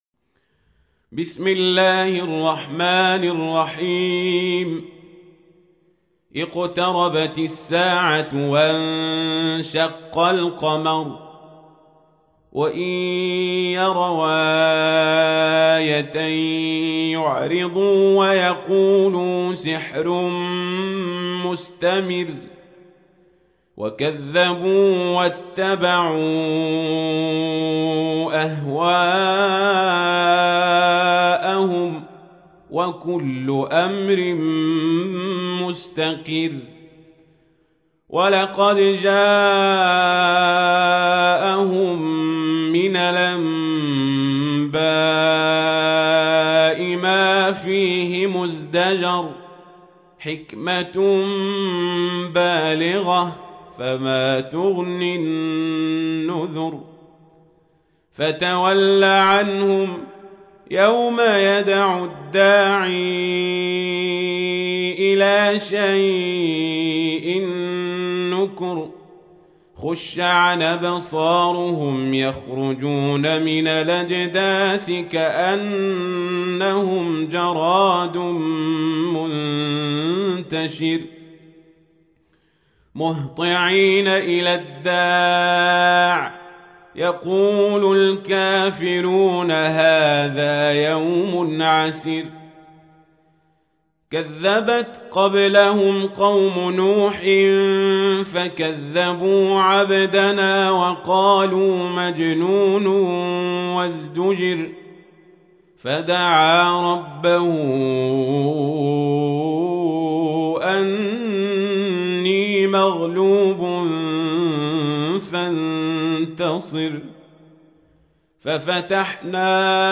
تحميل : 54. سورة القمر / القارئ القزابري / القرآن الكريم / موقع يا حسين